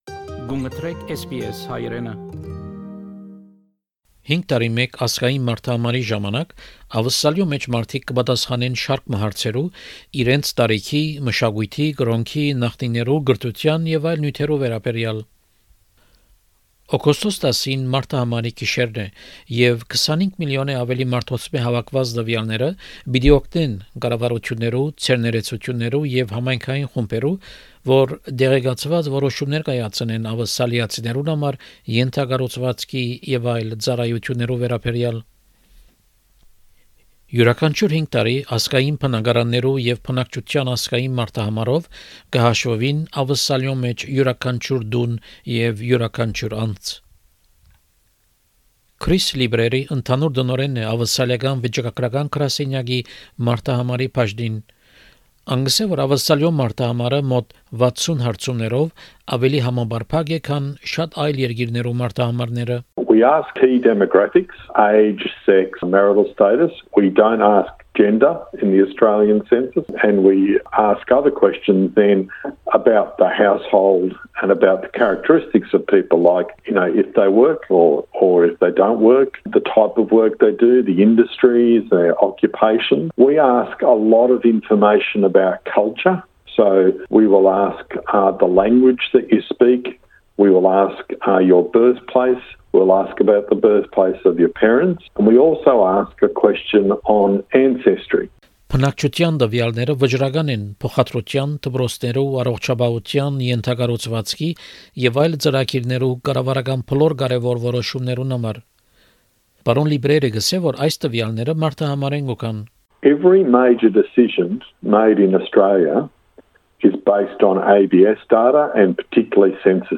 However, some people may be concerned about sharing their personal information and safety of their private data. In this episode of Settlement Guide, General Manager of the Census, Chris Libreri, is answering questions about these issues.